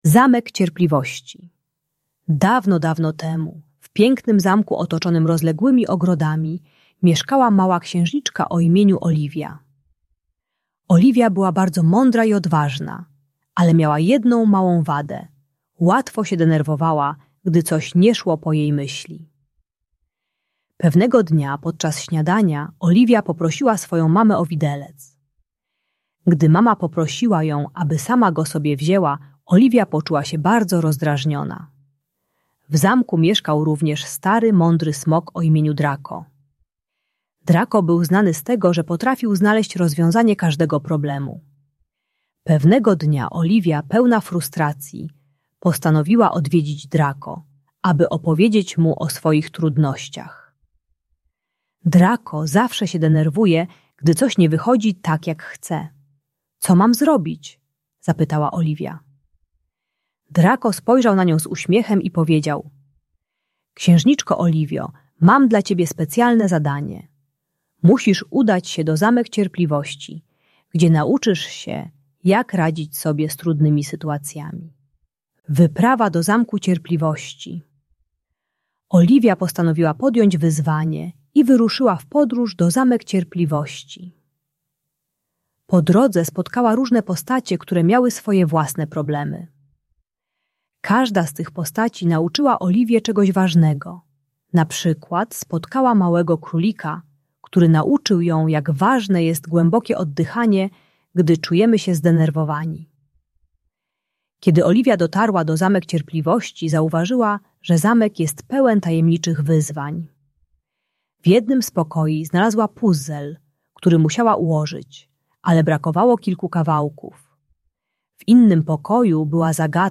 Opowieść o Zamek Cierpliwości - Bunt i wybuchy złości | Audiobajka